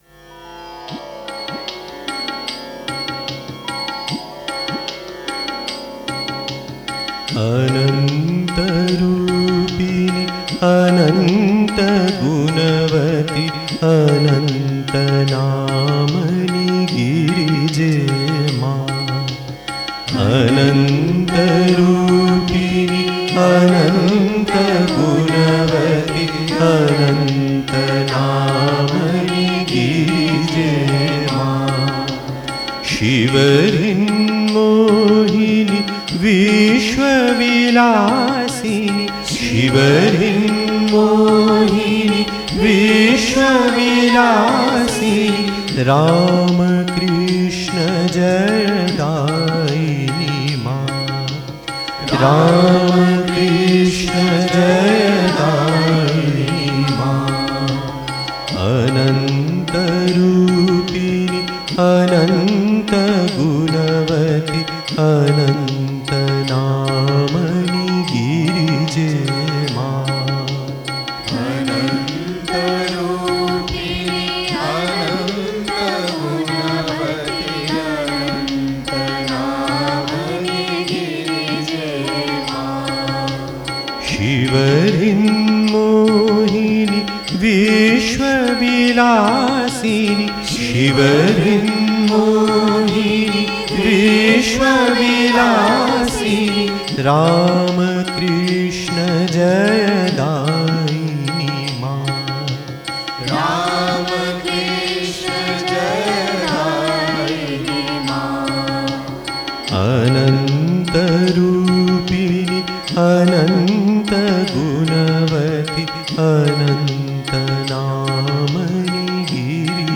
Aarti Song - 'Ananta Rupini '